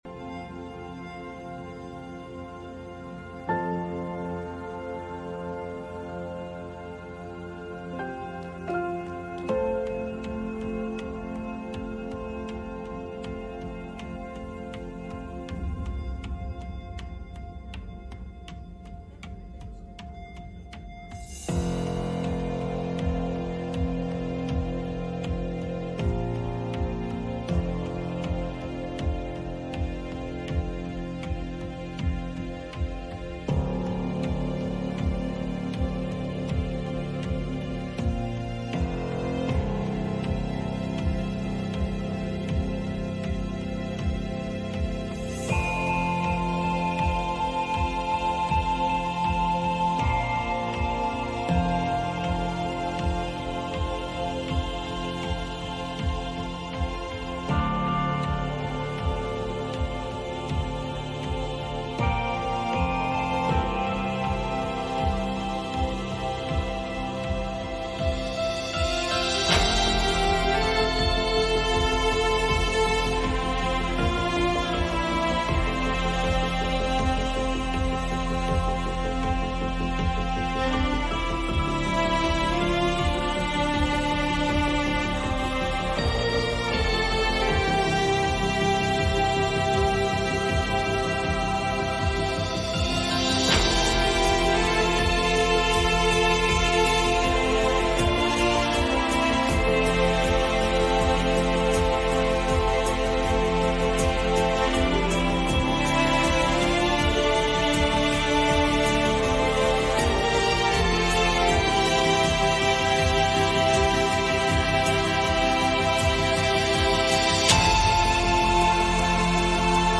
Passage: 1 Timothy 6:11 Service Type: Midweek Meeting